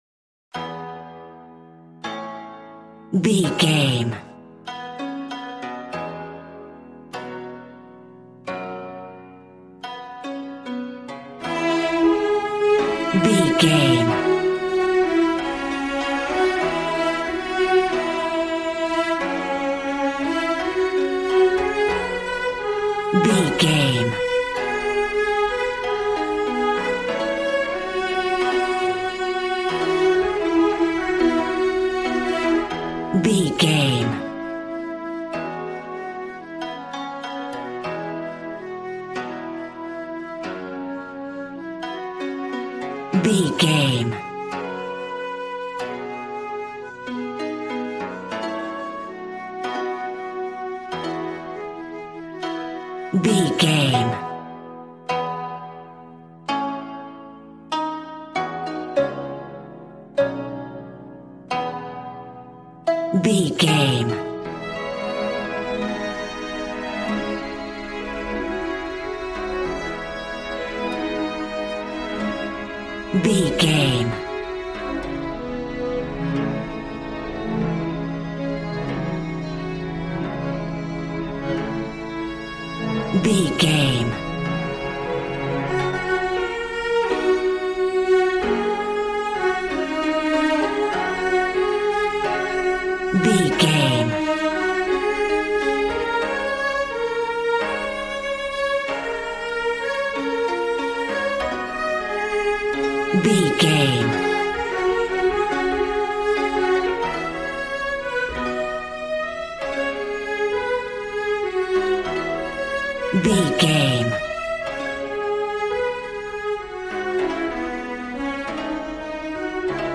Aeolian/Minor
A♭
happy
bouncy
conga